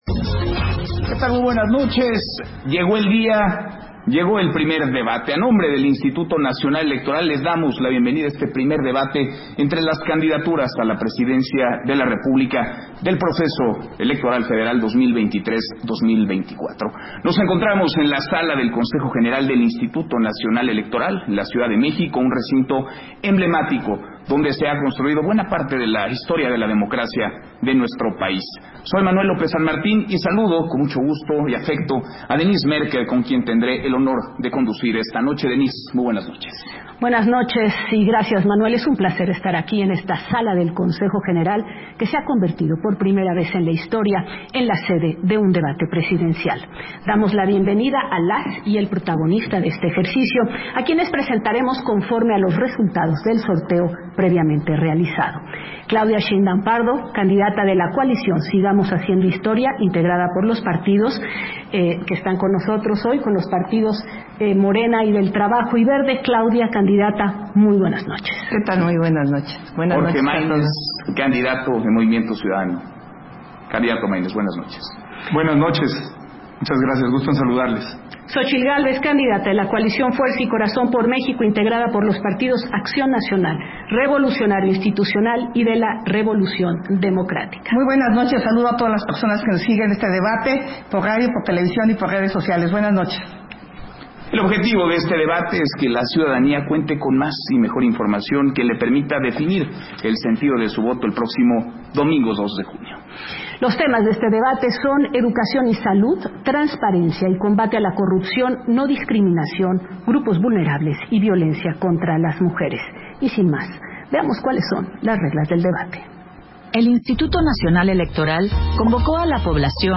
Versión estenográfica del Primer Debate entre las candidaturas a la Presidencia de la República del Proceso Electoral Federal 2023-2024, La sociedad que queremos